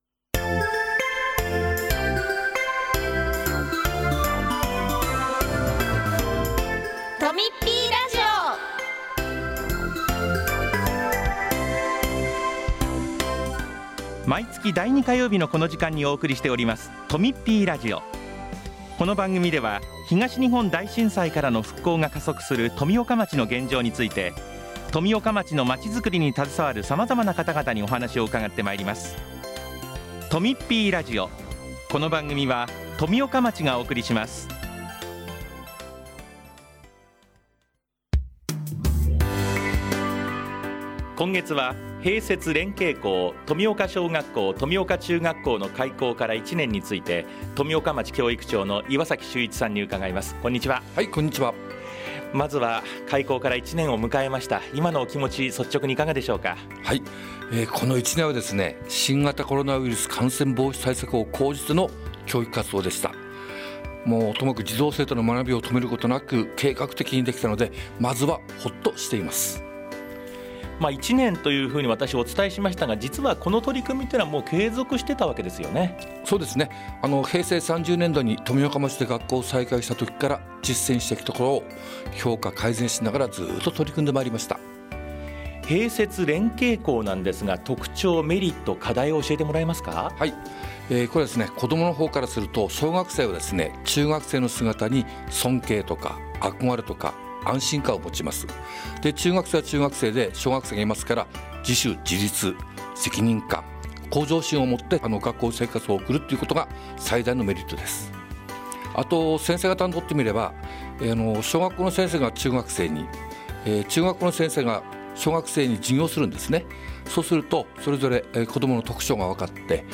4月11日（火曜日）に放送した「とみっぴーラジオ」を、お聴きいただけます。
今回は、併設・連携校「富岡小学校・富岡中学校」の開校から1年について、岩崎秀一教育長が紹介します。その他、町からのお知らせもあります。